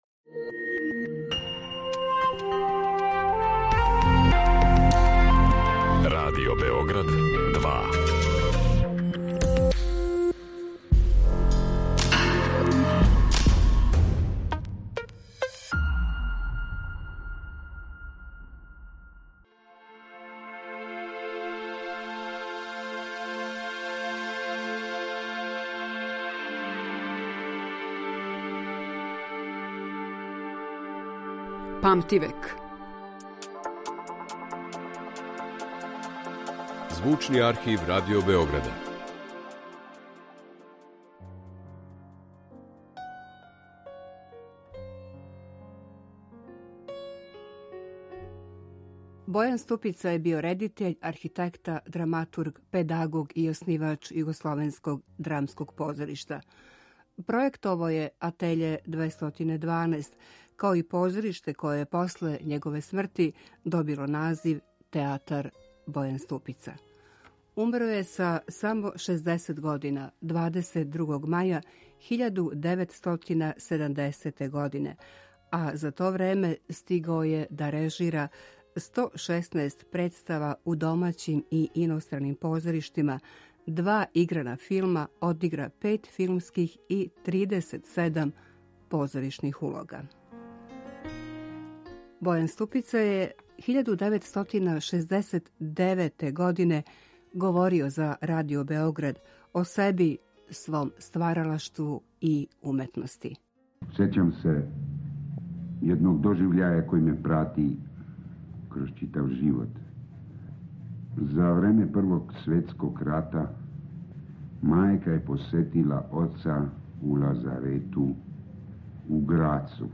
Слушамо како је Бојан Ступица говорио о себи, свом стваралаштву и уметности